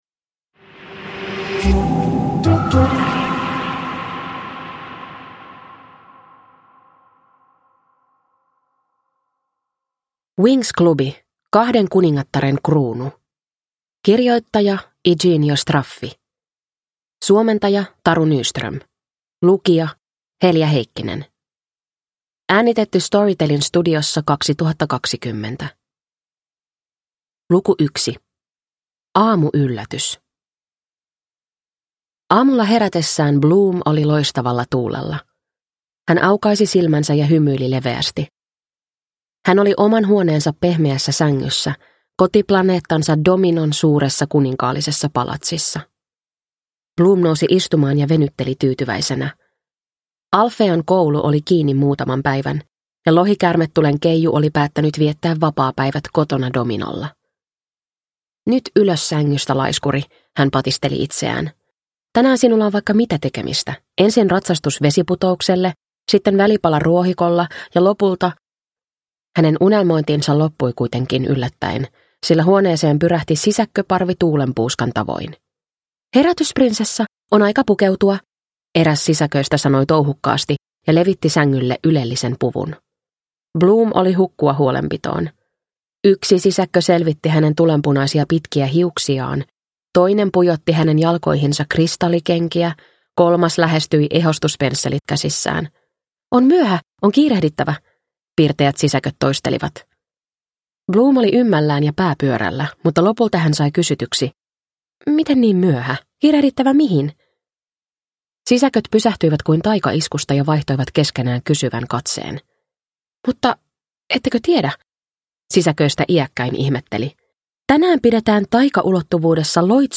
Winx - Kahden kuningattaren kruunu – Ljudbok